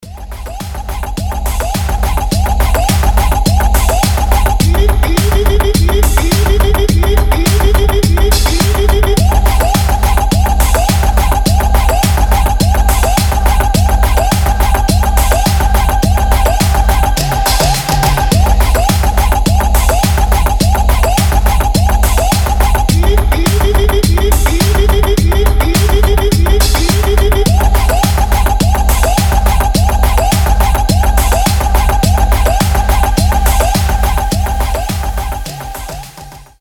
• Качество: 320, Stereo
громкие
мощные
веселые
Electronic
Bass House
bounce
Забавный клубняк)